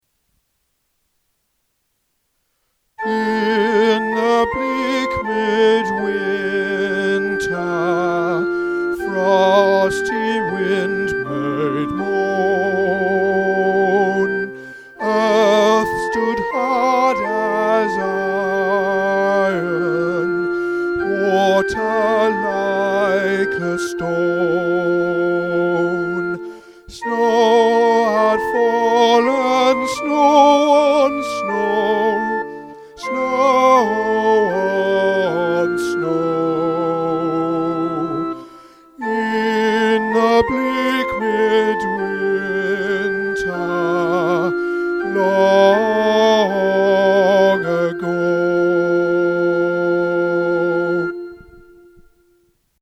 In The Bleak Midwinter – Soprano | Ipswich Hospital Community Choir
In-The-Bleak-Midwinter-Soprano.mp3